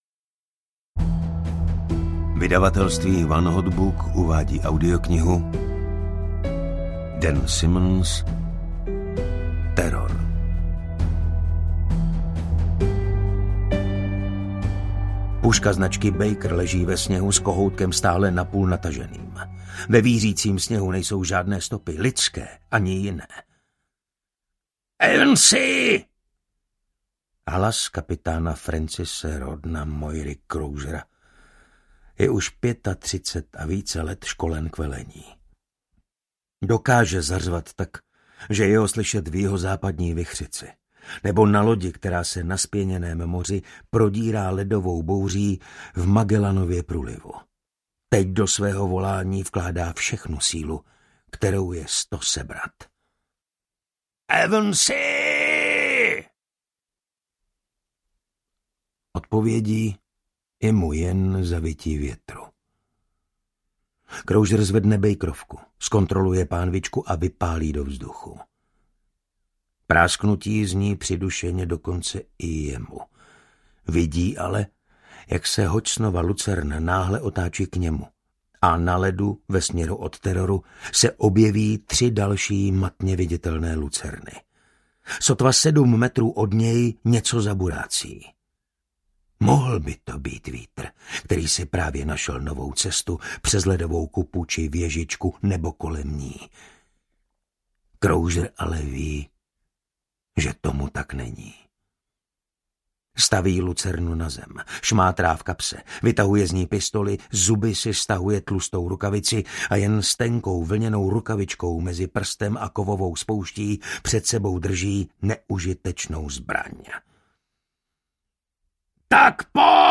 Terror audiokniha
Ukázka z knihy